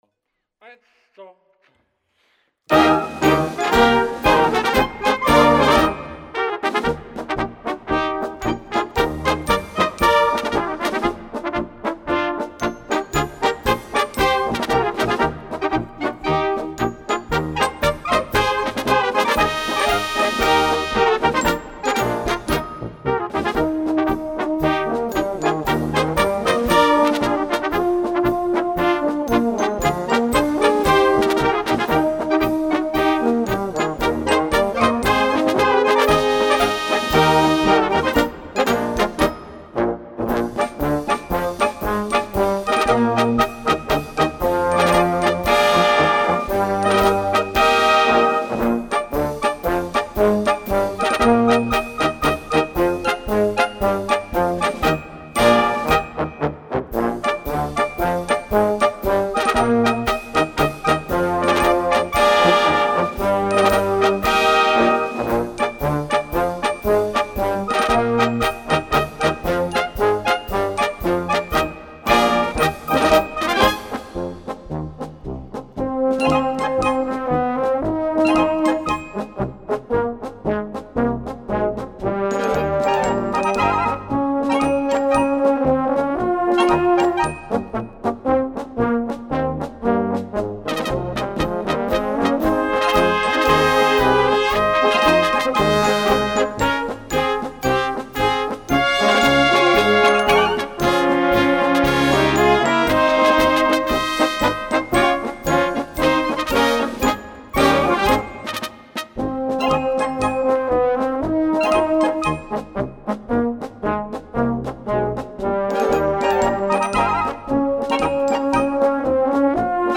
Straßenmarsch
Perfekt zum Marschieren